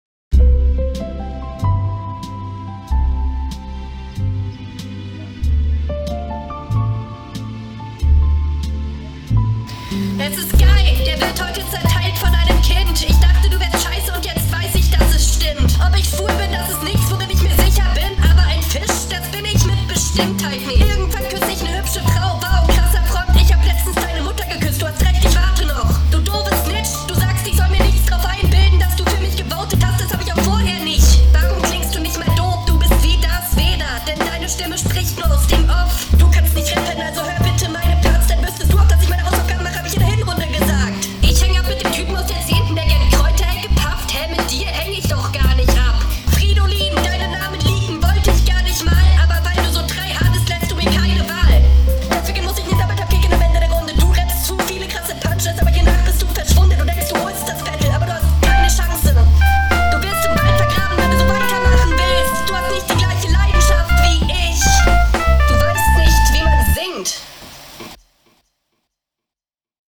Vom Flow her sagt mir das weniger zu als deine erste Runde.